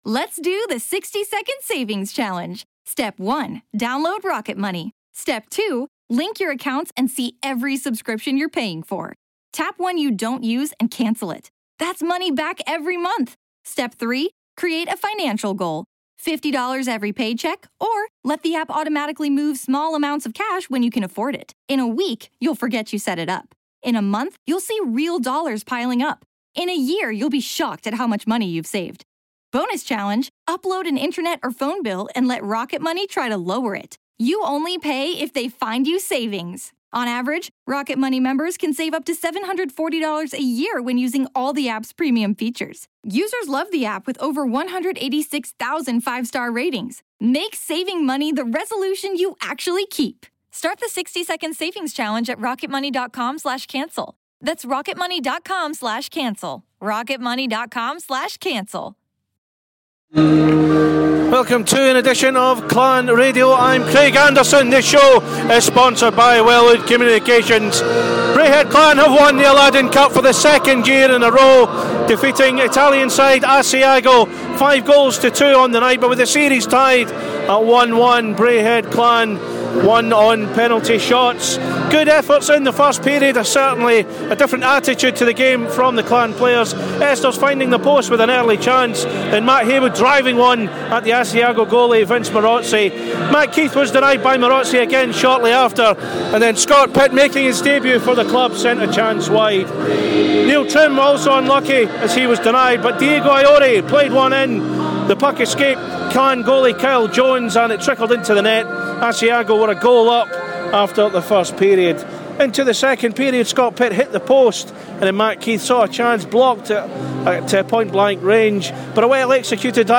There are interviews